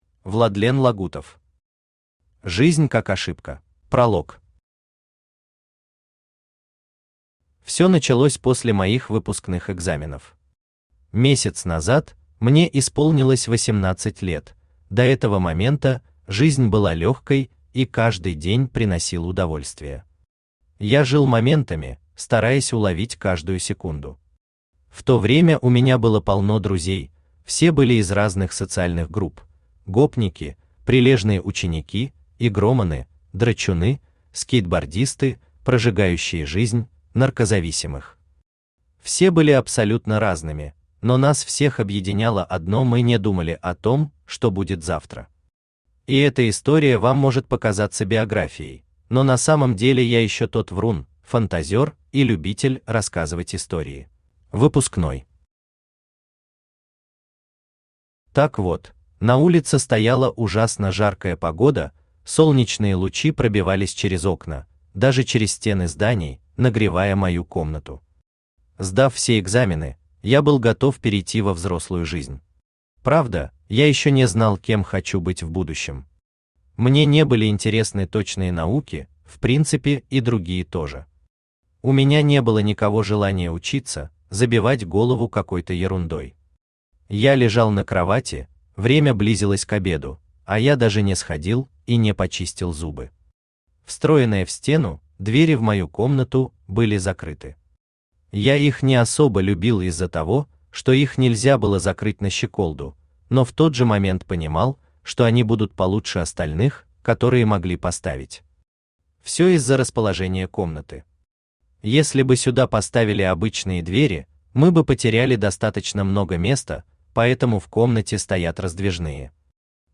Aудиокнига Жизнь как ошибка Автор Владлен Романович Лагутов Читает аудиокнигу Авточтец ЛитРес.